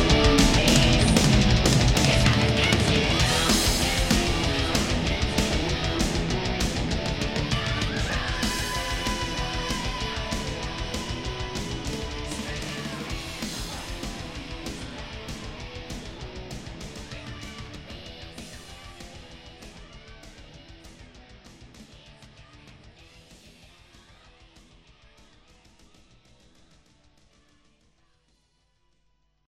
Help:Drum Fill - Programming